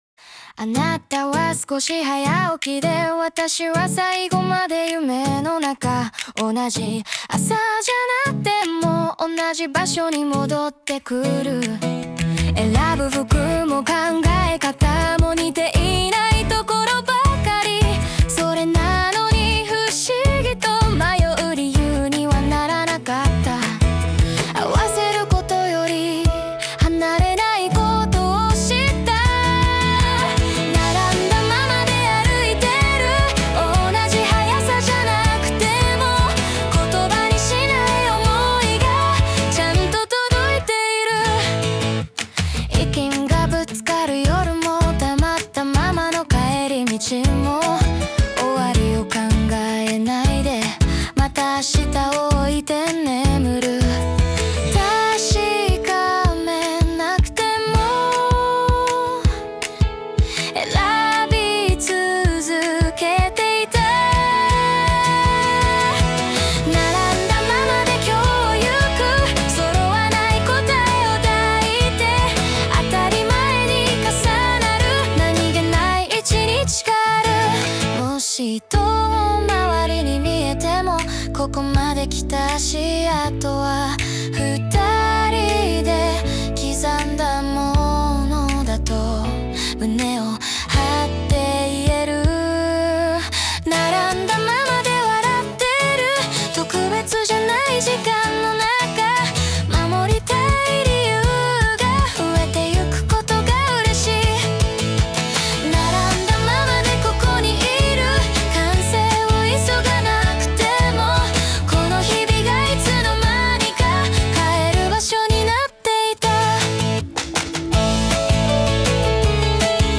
邦楽女性ボーカル著作権フリーBGM ボーカル
著作権フリーオリジナルBGMです。
女性ボーカル（邦楽・日本語）曲です。
少し短い曲ですが、メロディラインがけっこう気に入ってます♪♪